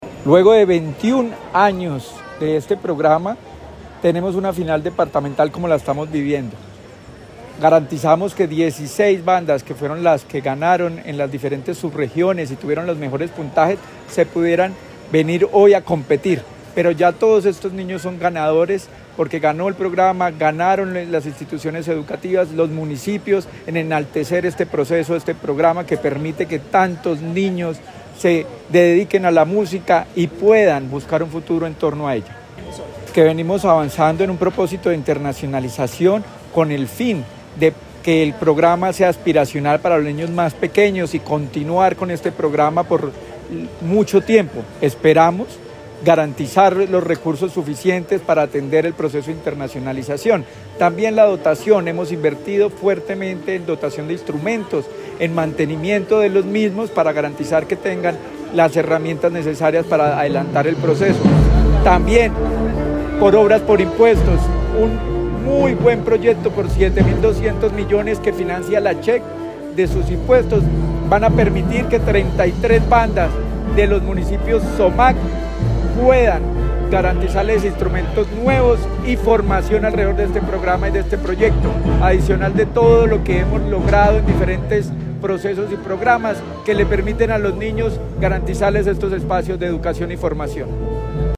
Secretario de Educación, Luis Herney Vargas Barrera.